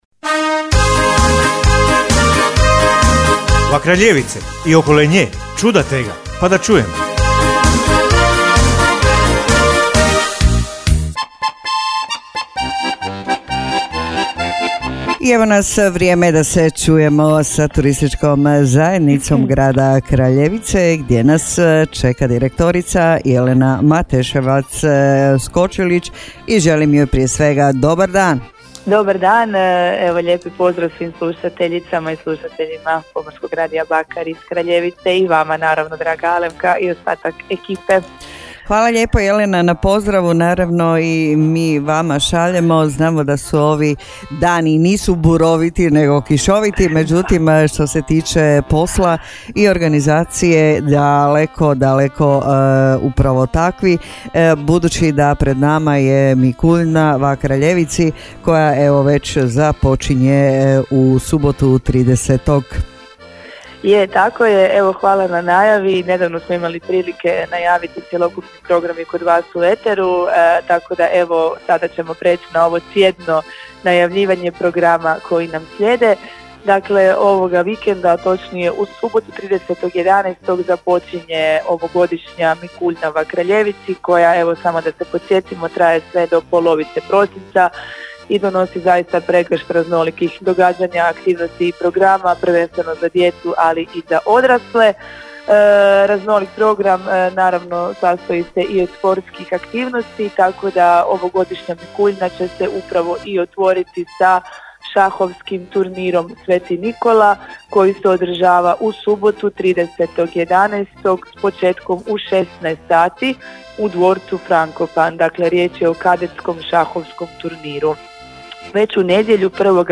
[Intervju]; MIKULJNA va Kraljevici 2024!